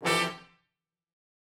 GS_HornStab-Dmin+9sus4.wav